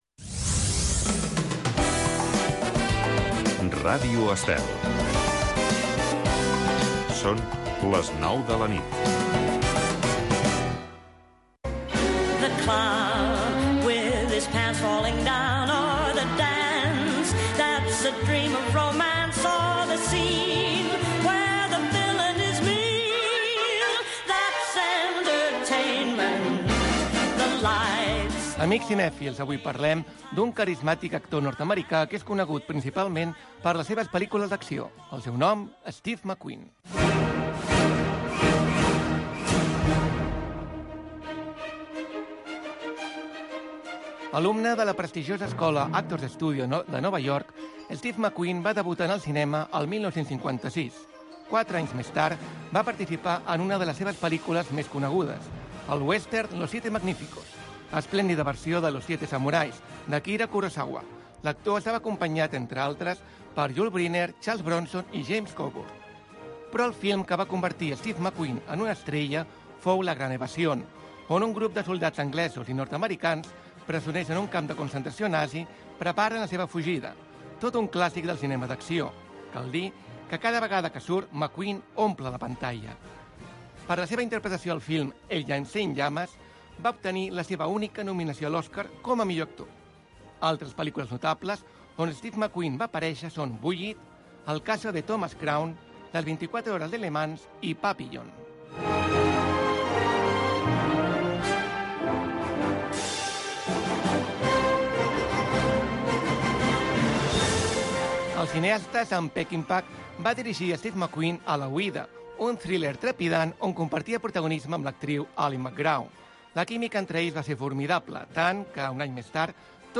Oh la la, la musique. Programa de música francesa.